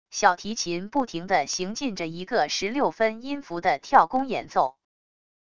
小提琴不停的行进着一个十六分音符的跳弓演奏wav音频